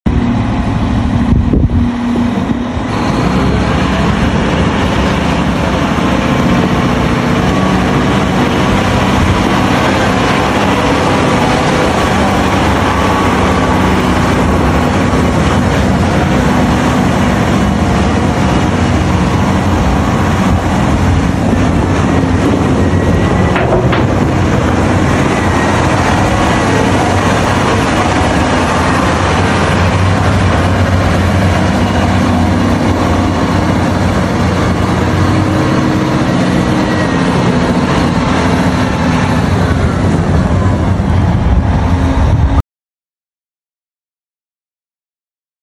One of our Two 2023 CAT 980’s in action.